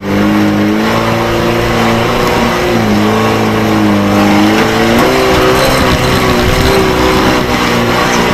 Index of /server/sound/vehicles/lwcars/uaz_452
rev.wav